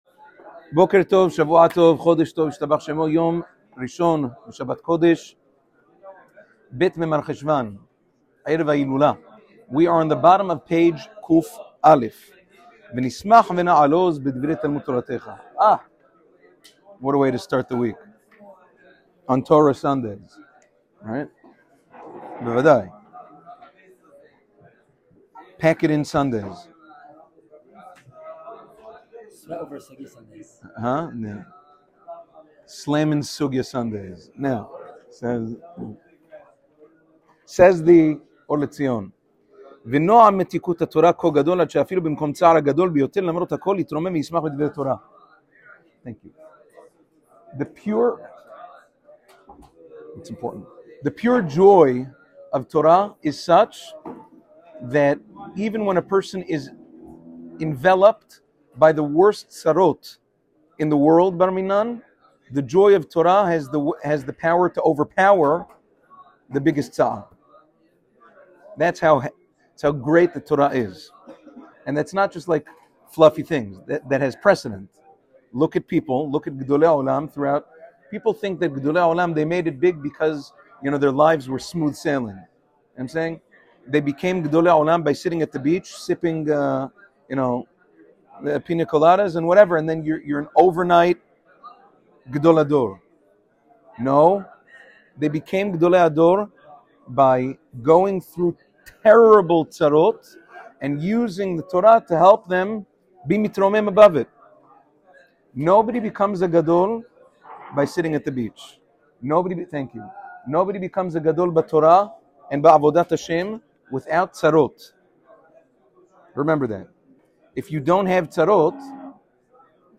Ohr letzion volume 2 shiur #92